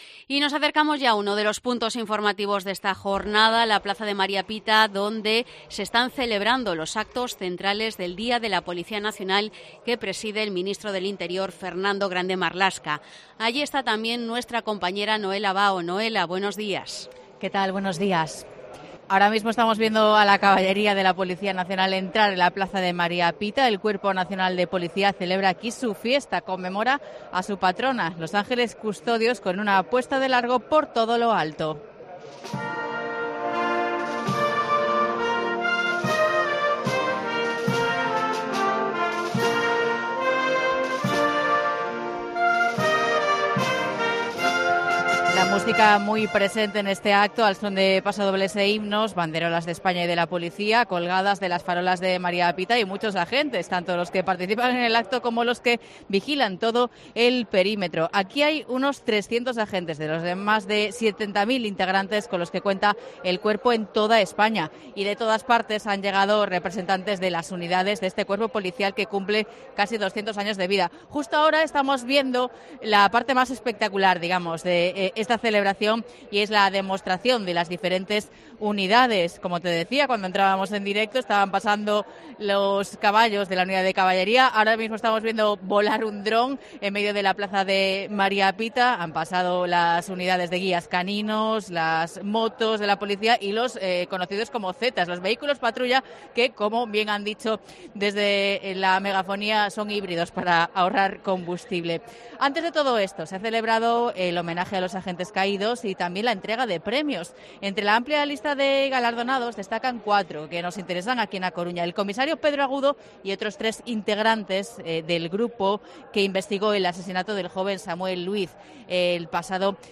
Crónica del Día de la Policía Nacional desde María Pita